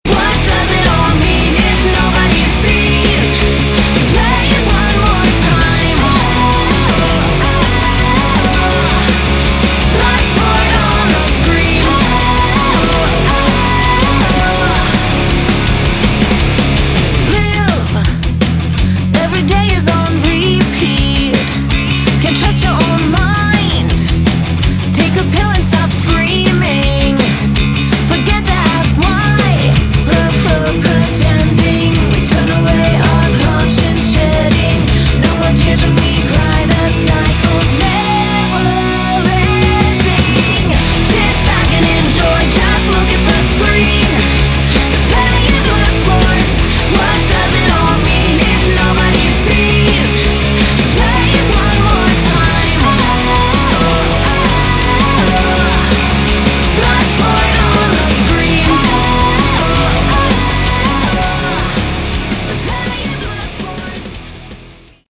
Category: Modern Hard Rock
guitars
drums